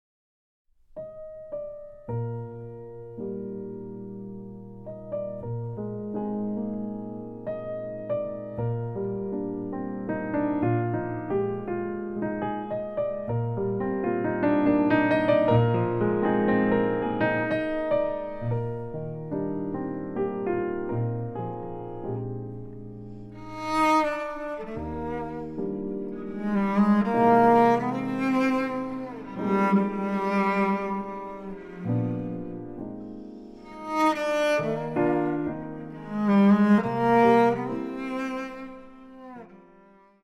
チェロのたおやかな音色による実直な美の結晶であること。
チェロ
ピアノ